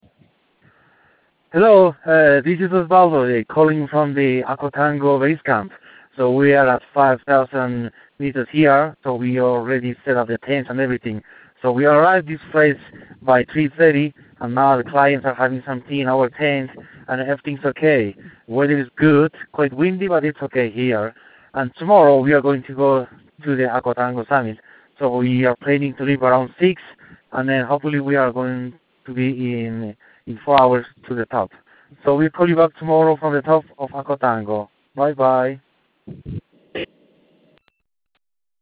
Bolivia Expedition Dispatch